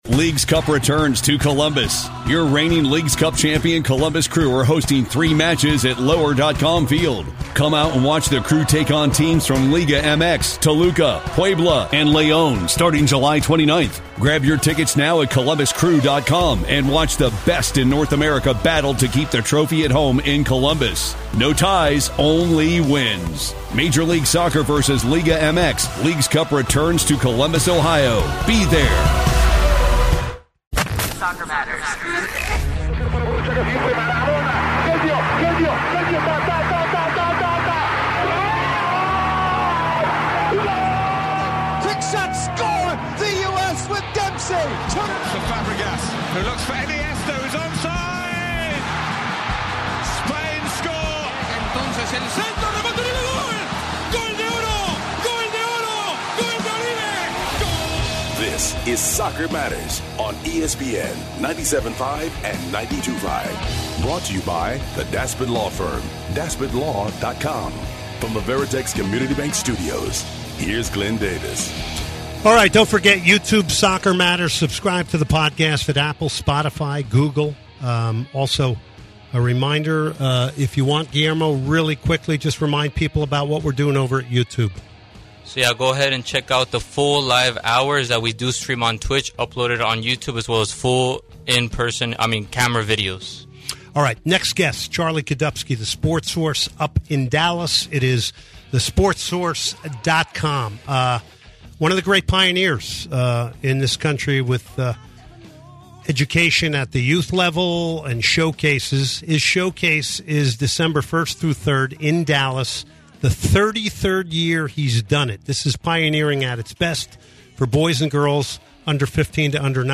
one live in studio interview